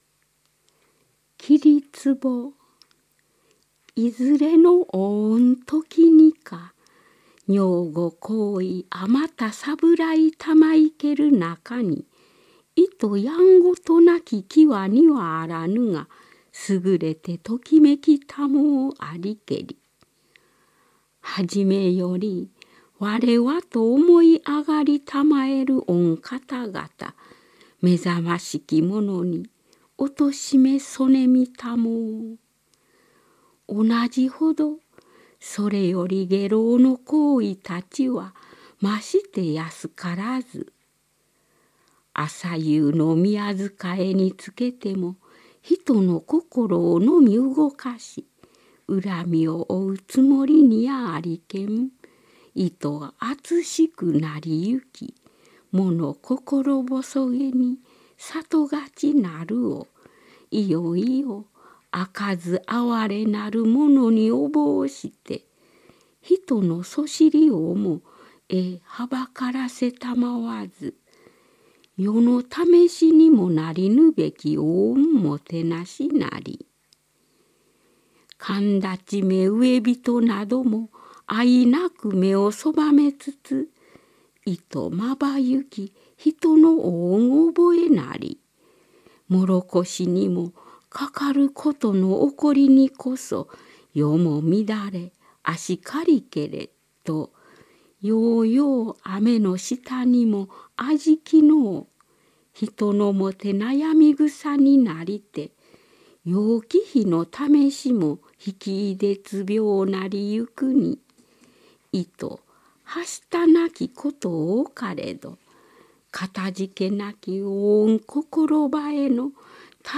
「源氏物語」朗読 - 試聴可
平安時代の発音の面影をいまに伝へる
その地域出身者より採集された希少録音版